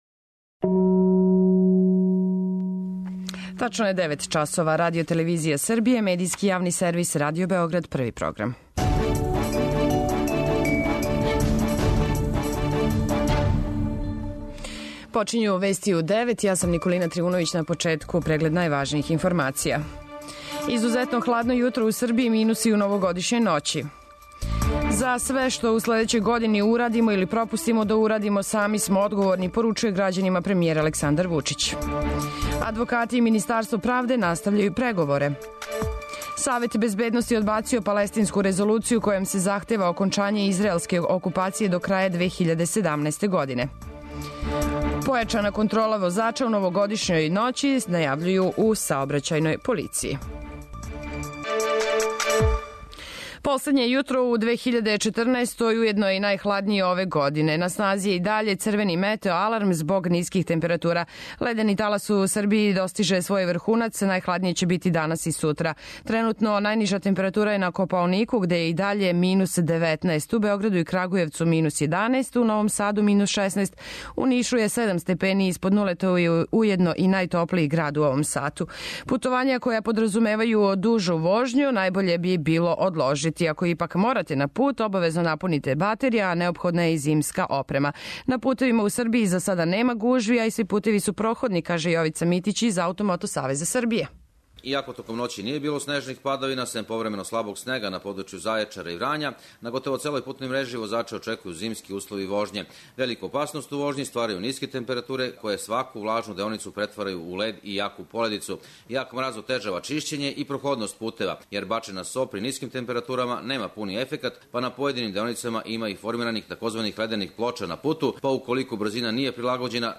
преузми : 10.54 MB Вести у 9 Autor: разни аутори Преглед најважнијиx информација из земље из света.